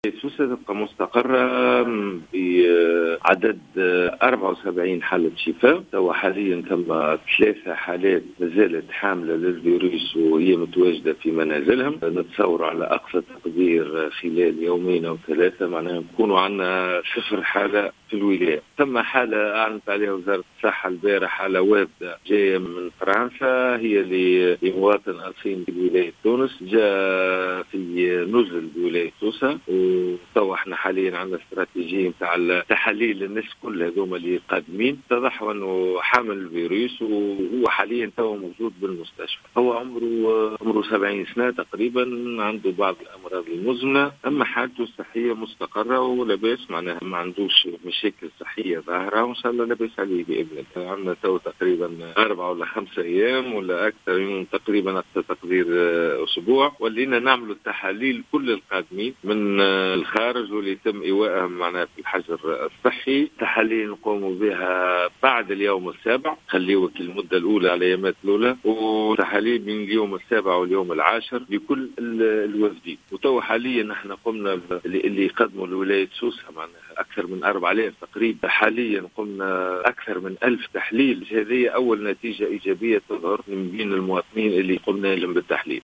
أكد المدير الجهوي للصحة بسوسة، سامي الرقيق في تصريح اليوم لـ"الجوهرة أف أم" أنه لم يتم تسجيل أي إصابات محلية جديدة بفيروس "كورونا" منذ تاريخ 24 أفريل الماضي.